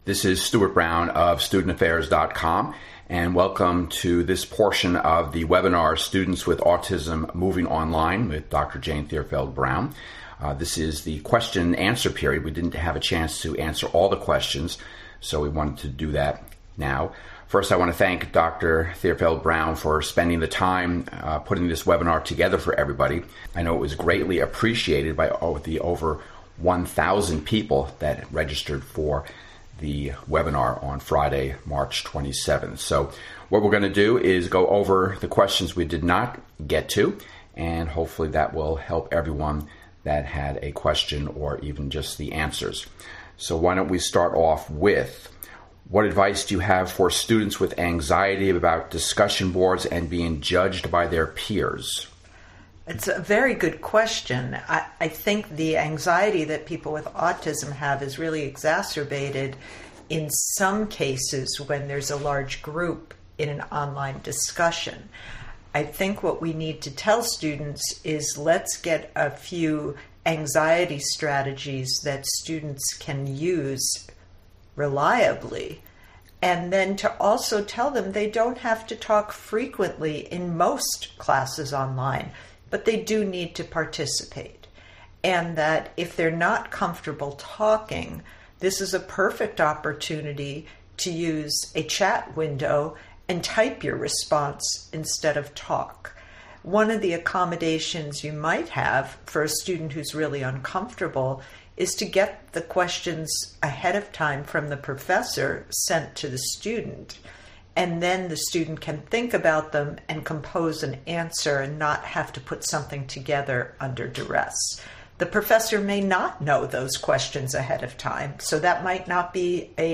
Follow up Questions and Answers:
Students-with-Autism-Moving-Online_QandA-Responses.mp3